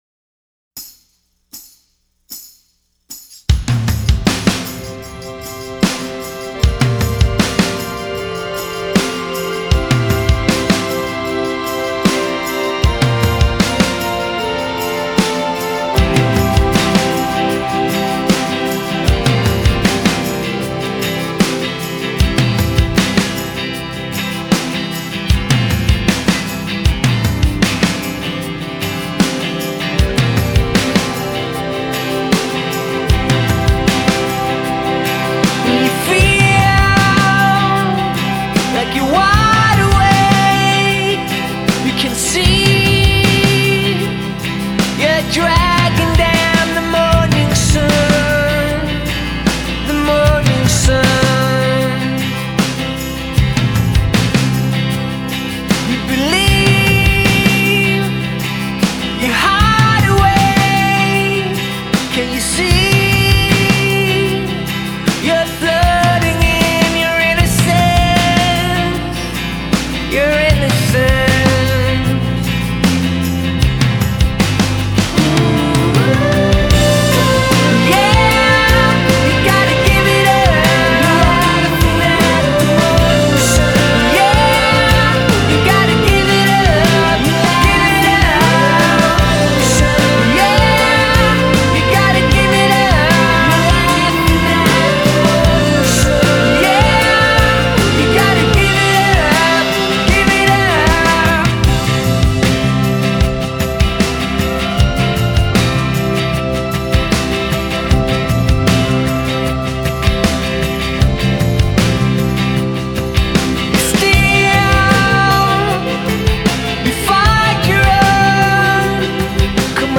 Elegant and powerful